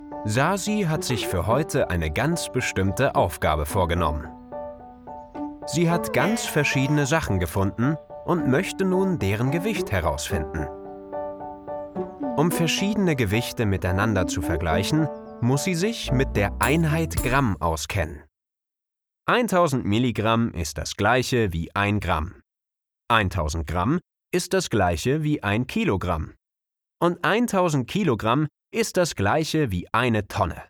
Male
Yng Adult (18-29)
My voice is a blend of youthful energy and seasoned charm, ensuring each text resonates fully. Warm, soothing, witty, and versatile, I infuse vibrancy into every word.
E-Learning
0503E_Learning.mp3